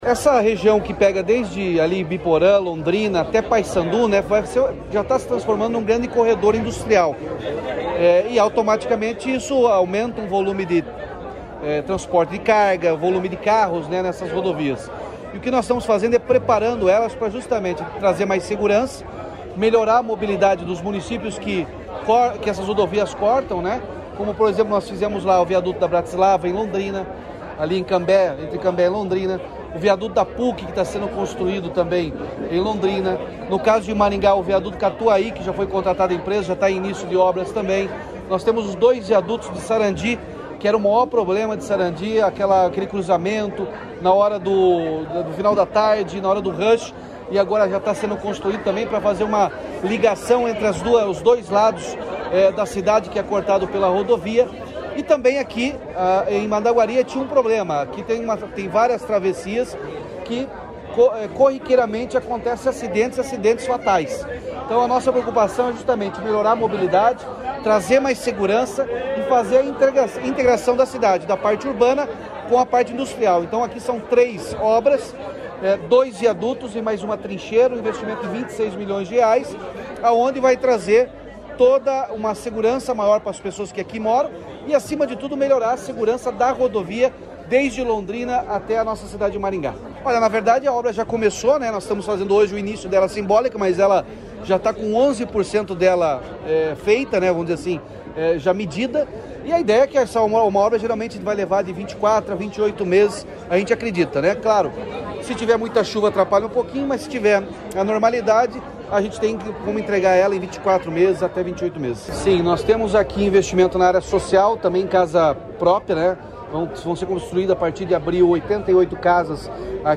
Sonora do governador Ratinho Junior sobre o convênio com Mandaguari para obras de dois viadutos e uma trincheira na PR-444